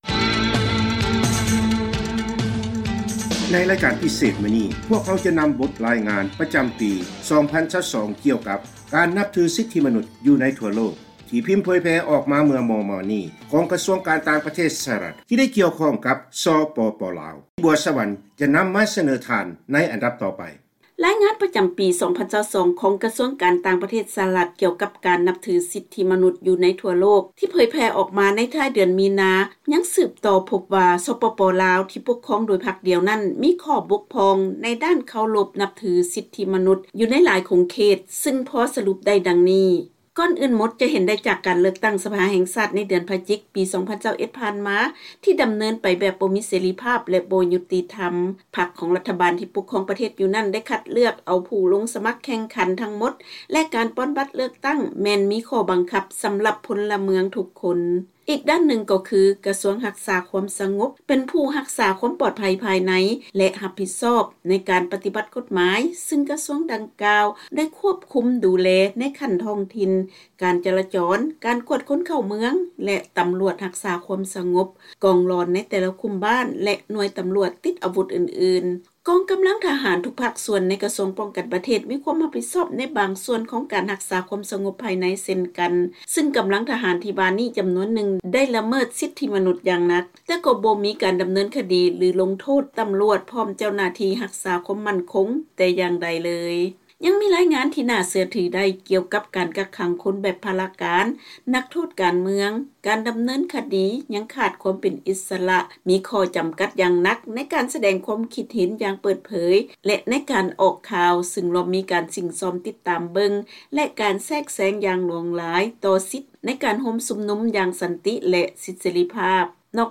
ເຊີນຟັງລາຍງານກ່ຽວກັບການລະເມີດສິດທິມະນຸດໃນ ສປປ ລາວໃນລາຍງານປະຈຳປີ 2022 ຂອງ ກຊ ການຕ່າງປະເທດ ສຫລ